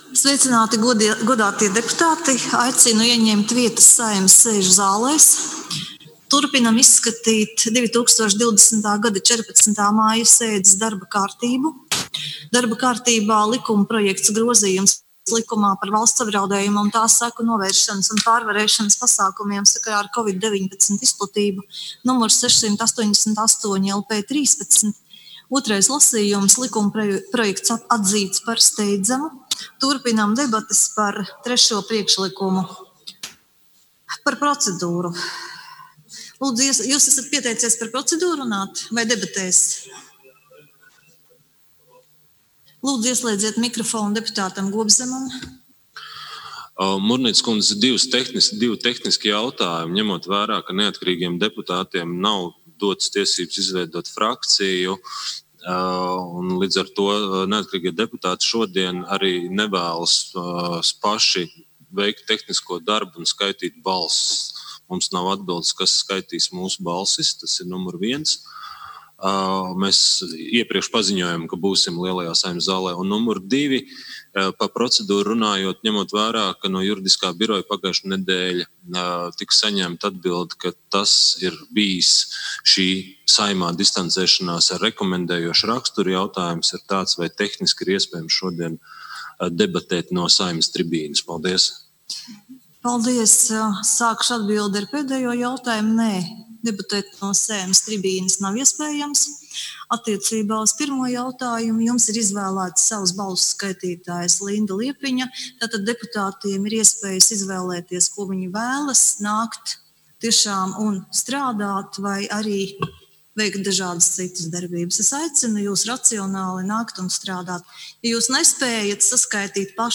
Latvijas Republikas 13. Saeimas pavasara sesijas piecpadsmitās (ārkārtas) sēdes turpinājums 2020. gada 19. maijā
Sēdi vada Latvijas Republikas 13. Saeimas priekšsēdētāja Ināra Mūrniece.